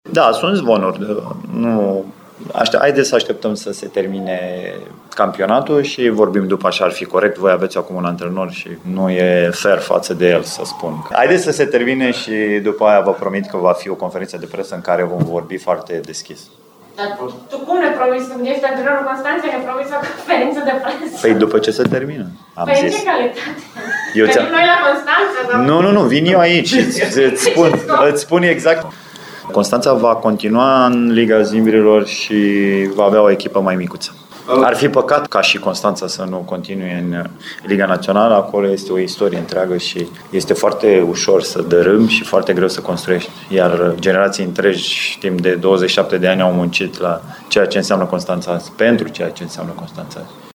La finalul partidei de vineri, SCM Poli – CSM Constanța 32-28, la care vizitatorii au avut doar zece jucători pe foaia de arbitraj, George Buricea a fost chestionat asupra unei posibile veniri la Timișoara: